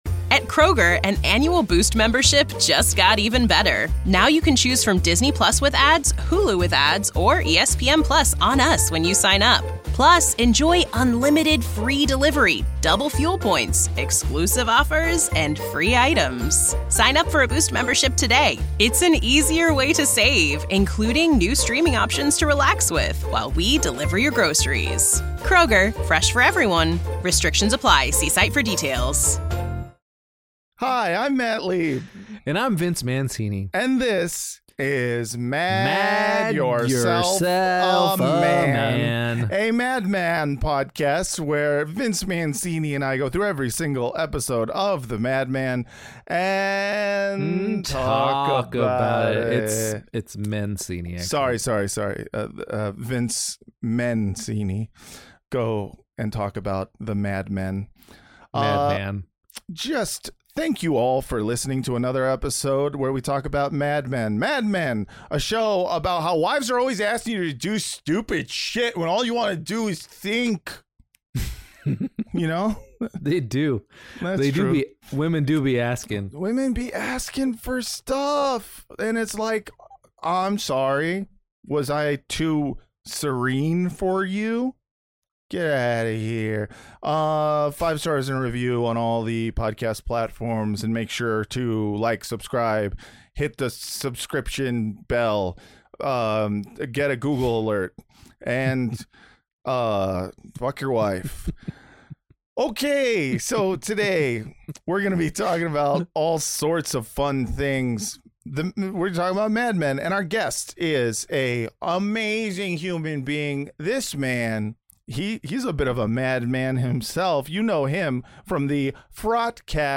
you love his deep voice